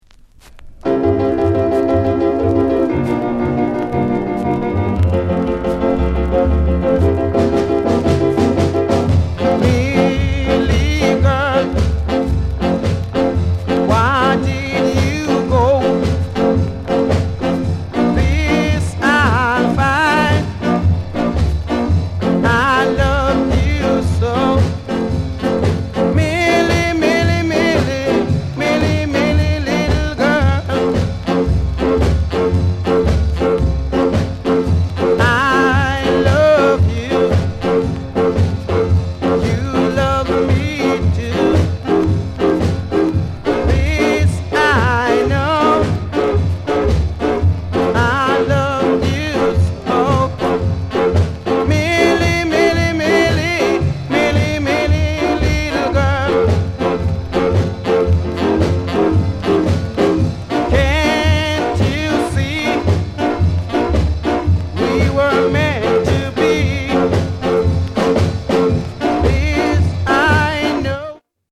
KILLER SKA